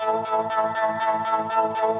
SaS_MovingPad02_120-A.wav